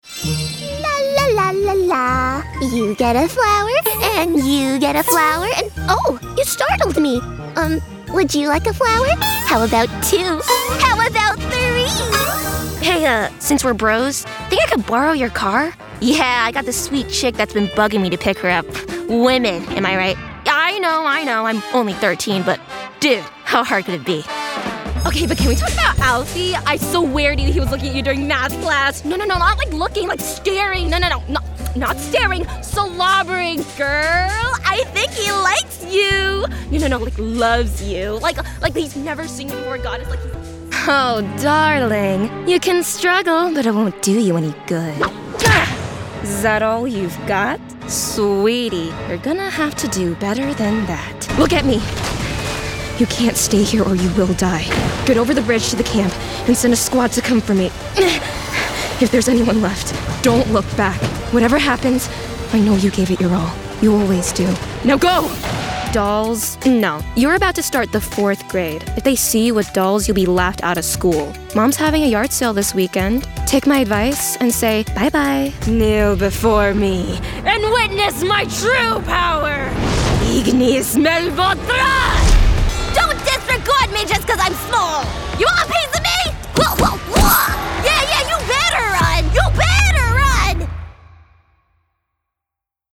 Child, Teenager, Young Adult
ANIMATION 🎬
rural/rustic
teenager
broadcast level home studio